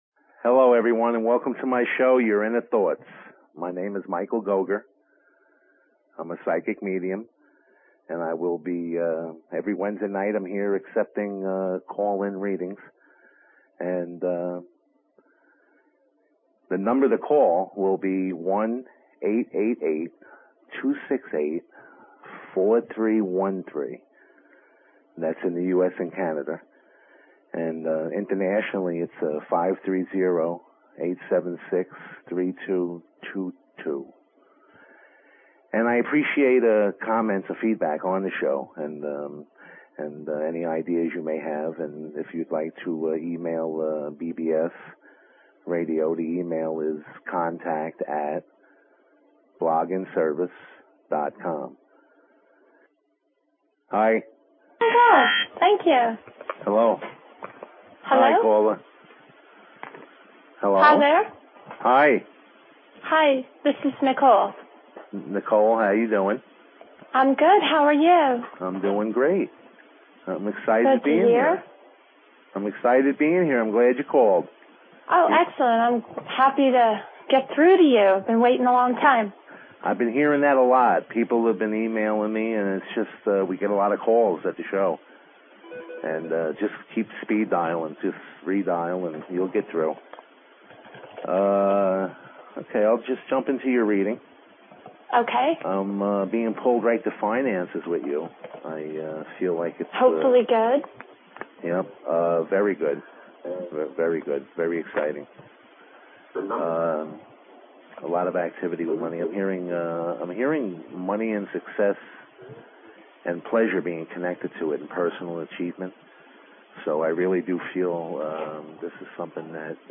Talk Show Episode, Audio Podcast, Your_Inner_Thoughts and Courtesy of BBS Radio on , show guests , about , categorized as